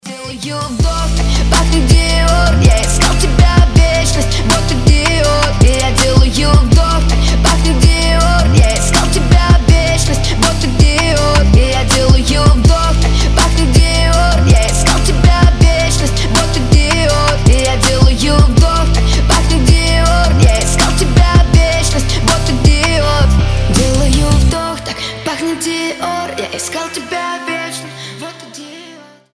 • Качество: 320, Stereo
мужской голос
громкие